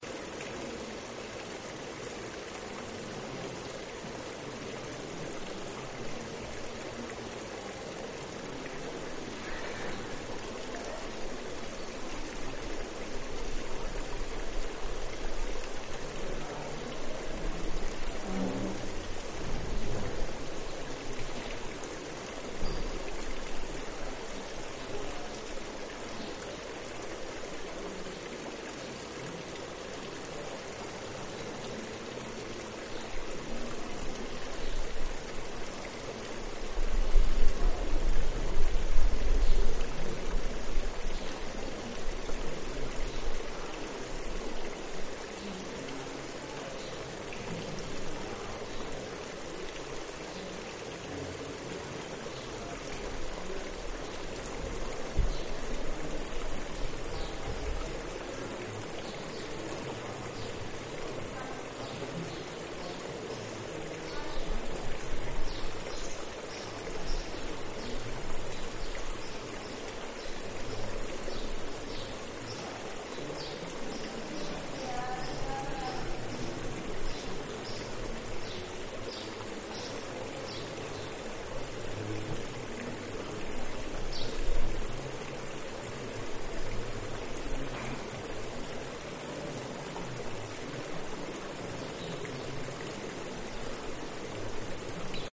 Historical soundscapes (c.1200 – c.1800). Granada. The sounds of water in the Patio de los Leones in the Alhambra
The sound of the Leones fountain. Iesalbayzin's recording